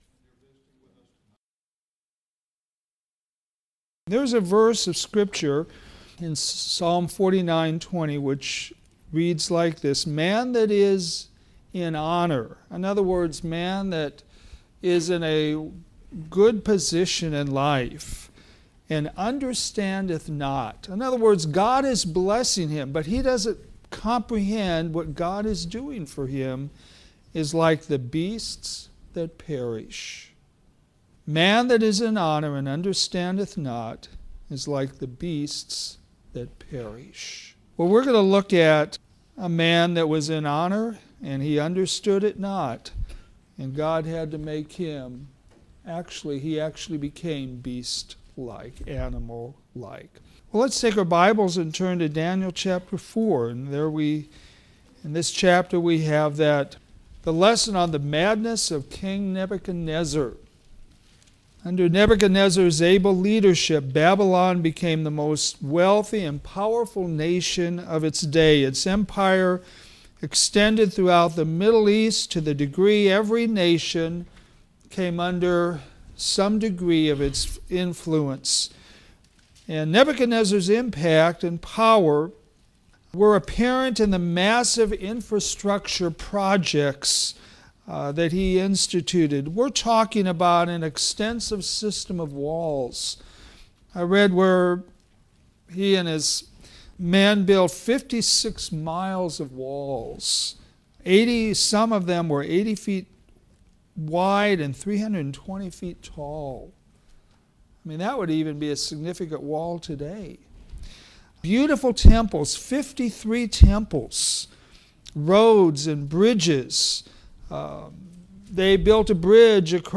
pride Sunday PM